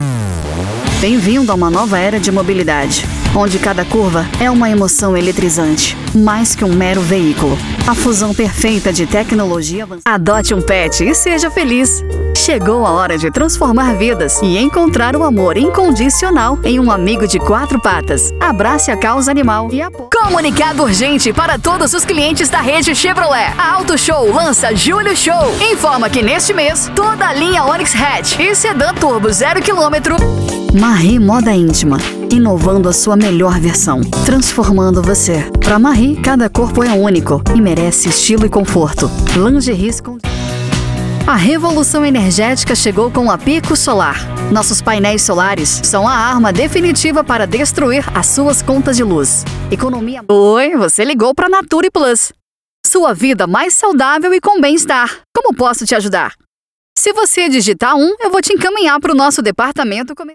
Spot Comercial
Vinhetas
Impacto
Animada
excelente locutora